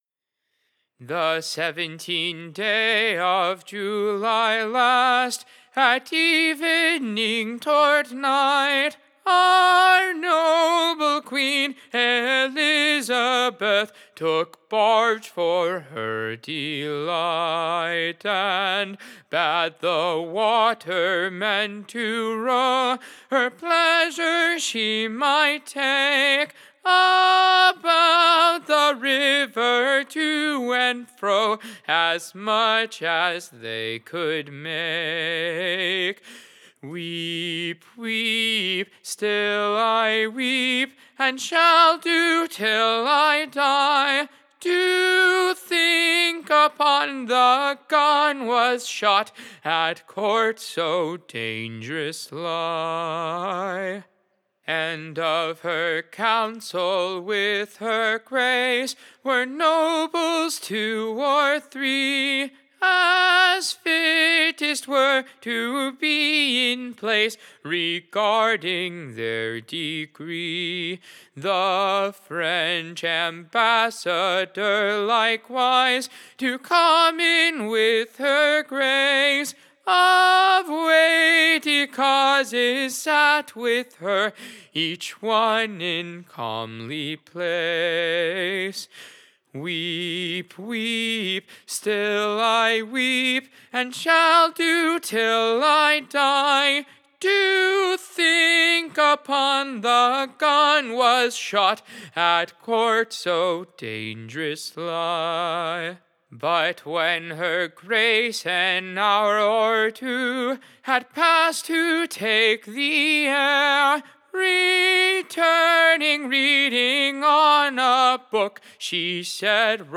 Recording Information Ballad Title A newe Ballade, declaryng the daungerons shootyng of the Gunne at the Courte. / To the tune of Sicke and sicke.